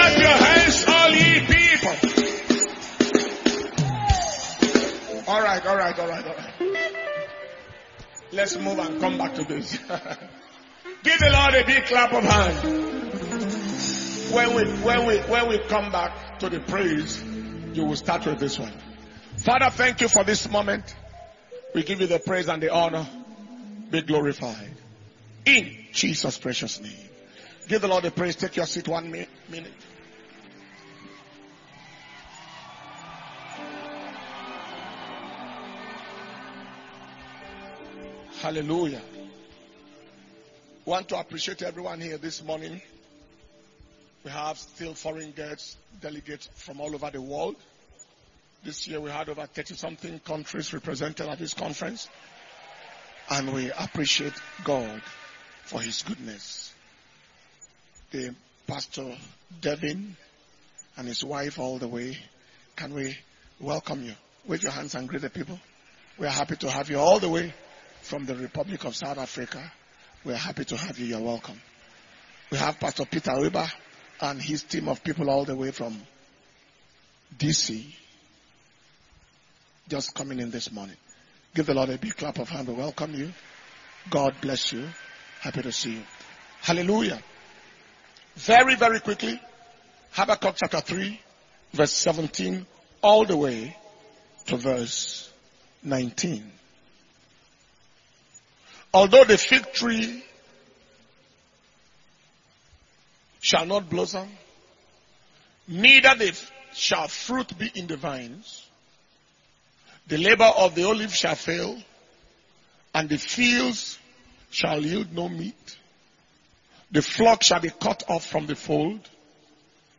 Destiny Recovery Convention Testimonies and Thanksgiving Service – Sunday 27th May 2022